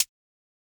Perc (17).wav